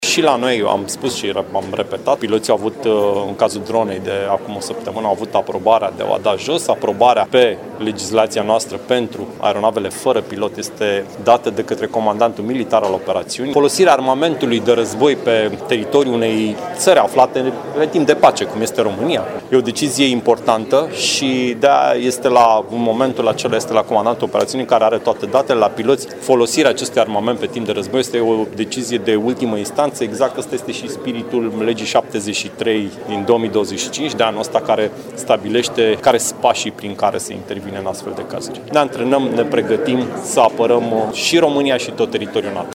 Prezent la Timișoara, Ionuț Moșteanu a reiterat că hotărârea de a doborî un avion străin care a intrat în spațiul NATO revine militarilor care conduc operațiunea de apărare.
Decizia de a doborî a unui avion străin intrat în spațiul aerian al NATO aparține militarilor care conduc operațiunea de apărare aeriană, a reiterat, la Timișoara, ministrul Apărării, Ionuț Moșteanu.